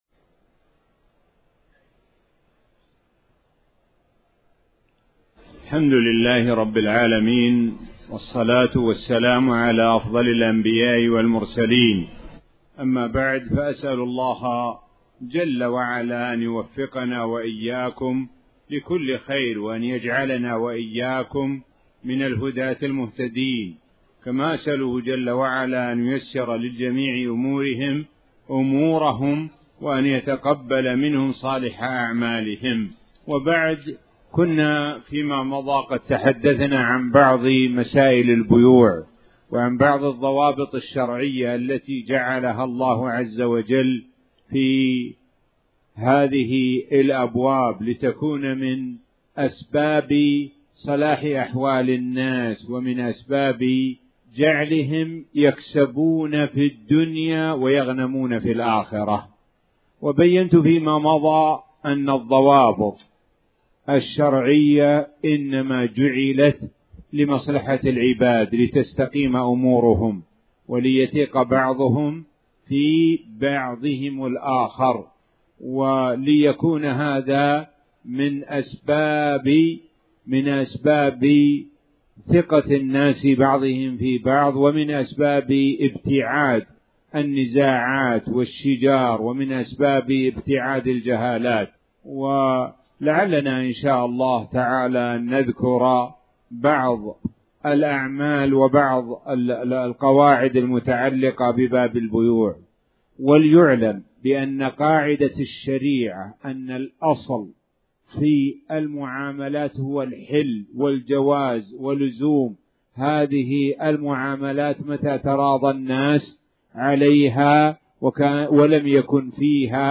تاريخ النشر ٦ ذو الحجة ١٤٣٩ هـ المكان: المسجد الحرام الشيخ: معالي الشيخ د. سعد بن ناصر الشثري معالي الشيخ د. سعد بن ناصر الشثري كتاب البيوع باب بيع السنين